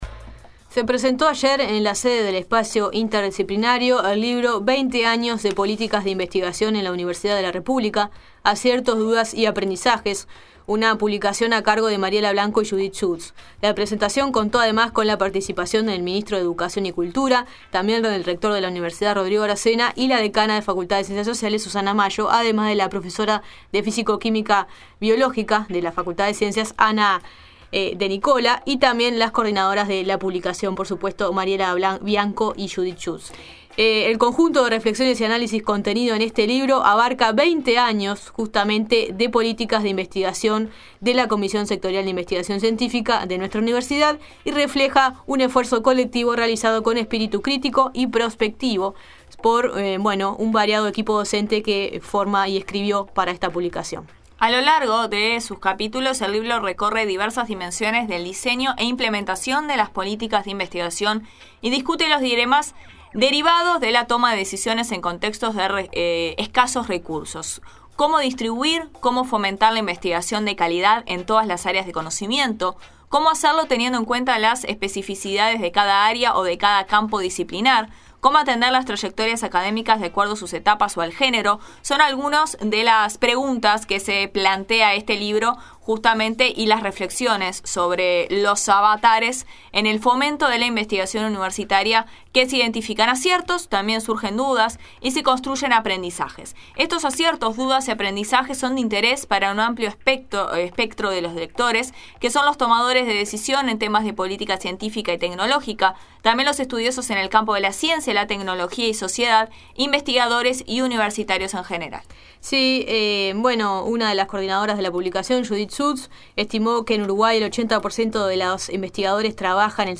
Dos decadas de investigacion en UdelaR, entrevista